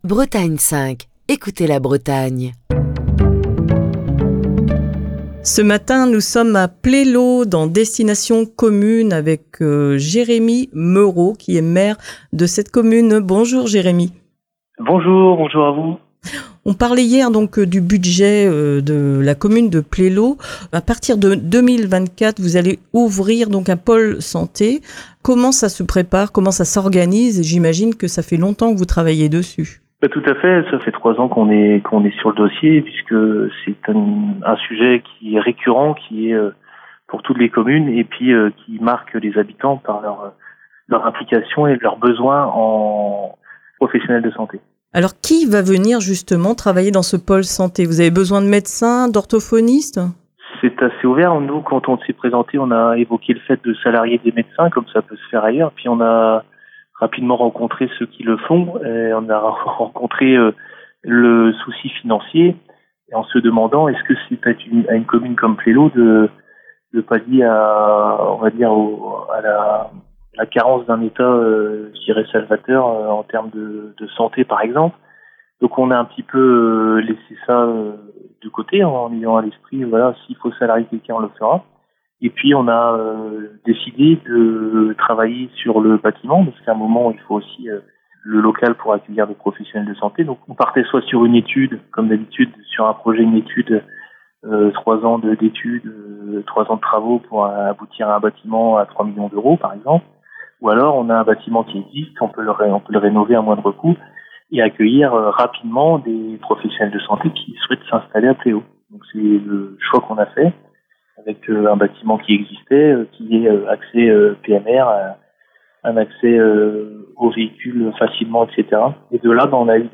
C'est à Plélo, dans les Côtes d'Armor, que Destination Commune fait escale cette semaine. Le maire de Plélo, Jérémy Meuro, est au téléphone